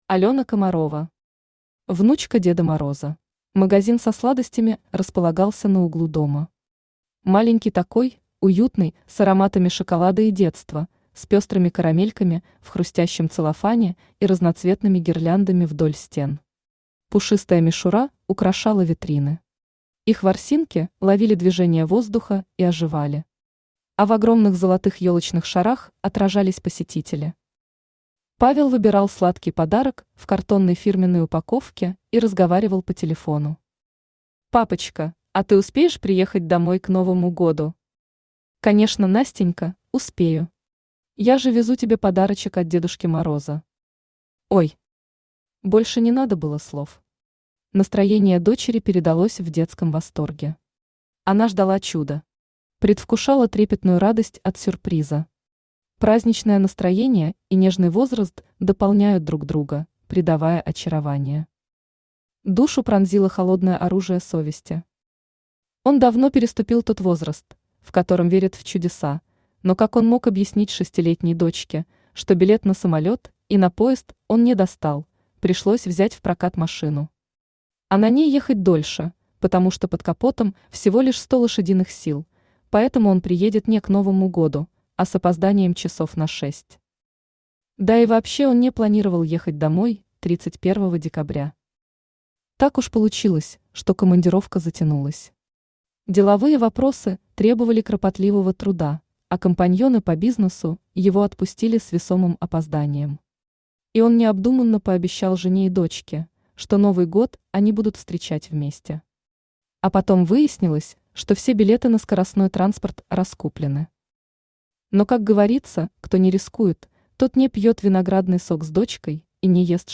Аудиокнига Внучка Деда Мороза | Библиотека аудиокниг